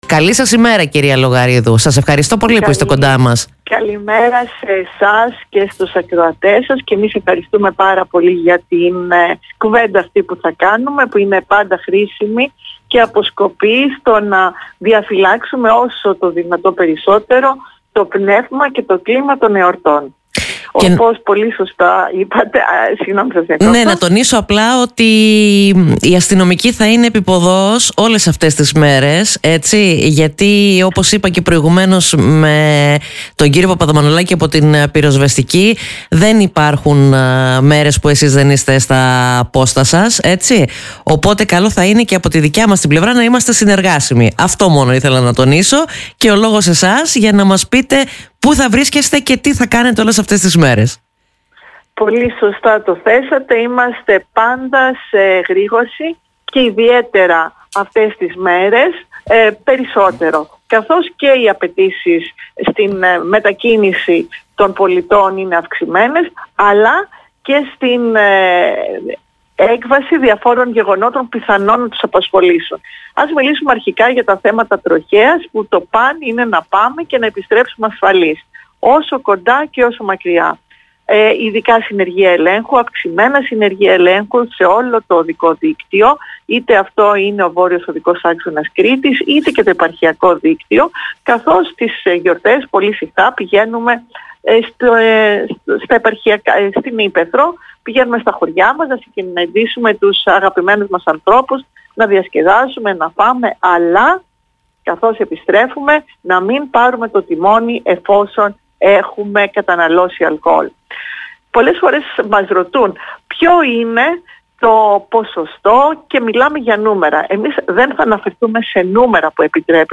Ακούστε τι είπε στον politica 89.8